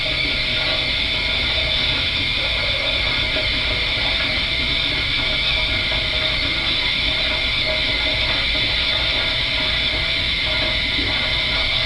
steam.wav